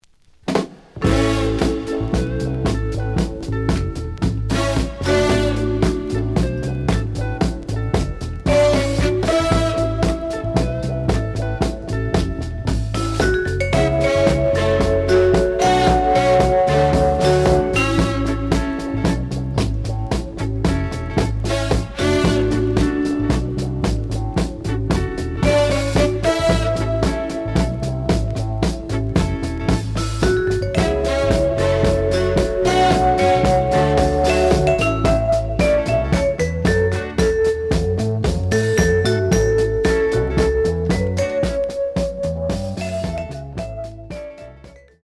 The audio sample is recorded from the actual item.
●Genre: Jazz Funk / Soul Jazz